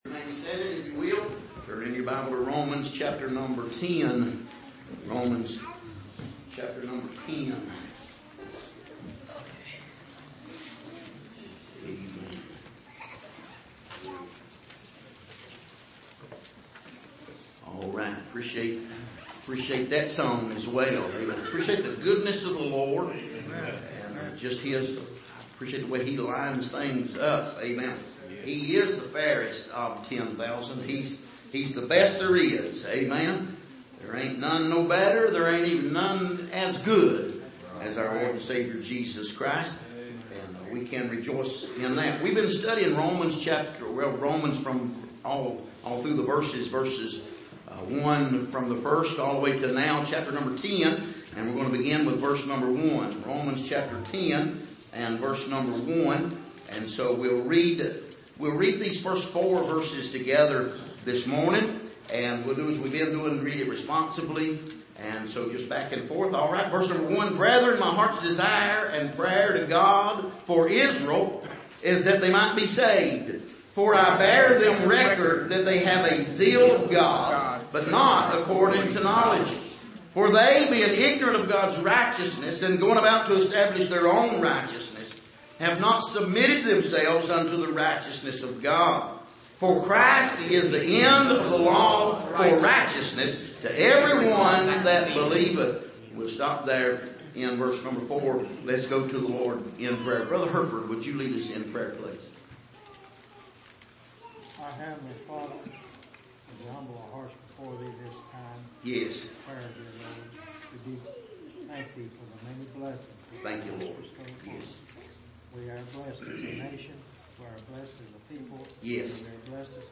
Passage: Romans 10:1-4 Service: Sunday Morning